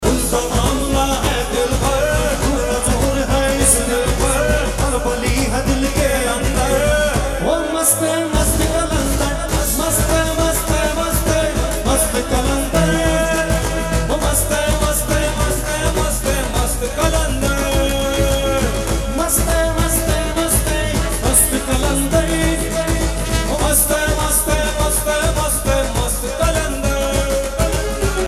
Dandiya Mix Ringtones